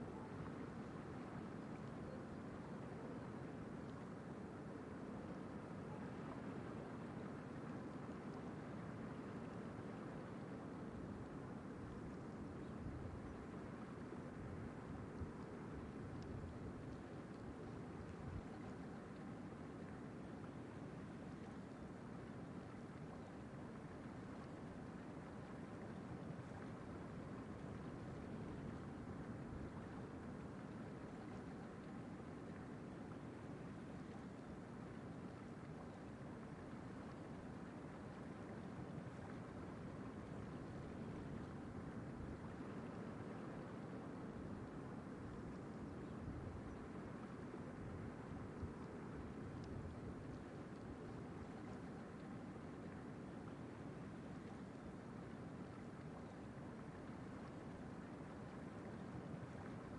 死亡的海岸 " 彭东海滩 AB 2M
Tag: 大洋 大海 沙滩 海浪